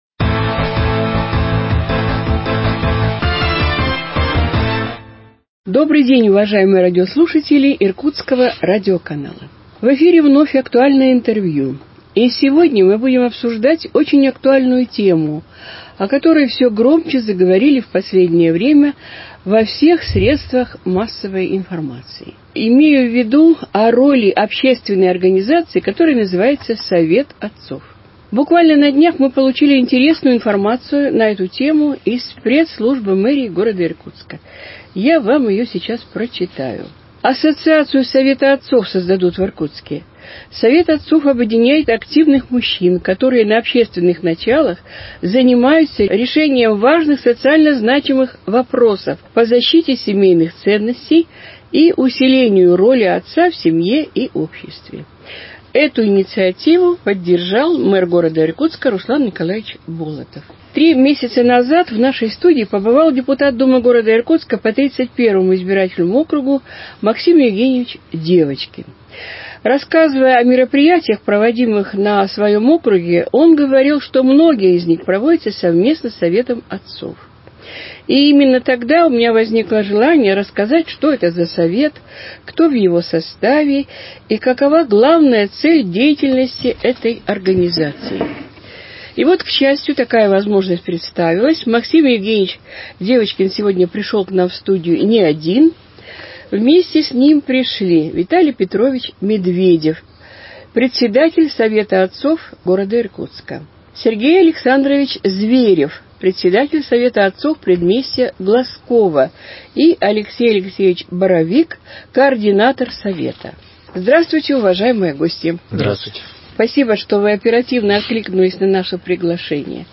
Актуальное интервью: Совет отцов Иркутска 24.05.2021